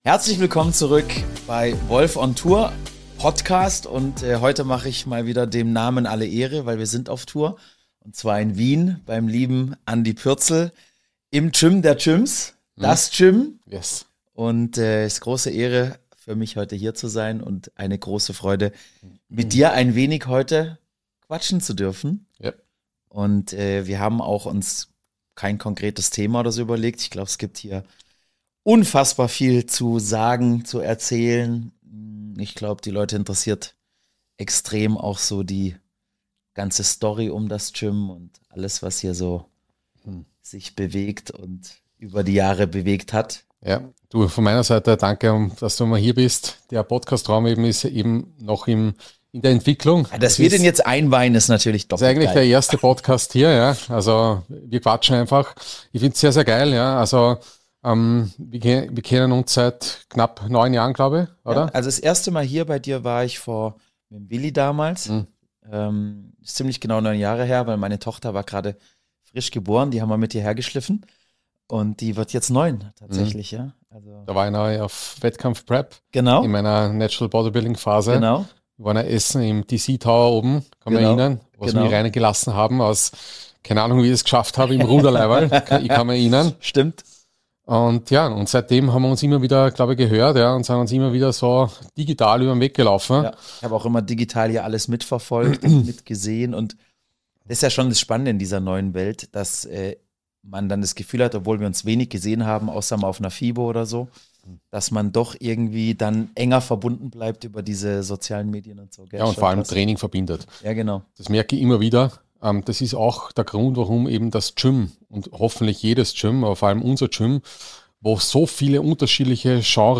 Heute sitzen wir im legendären DAS GYM Wien und lachen darüber.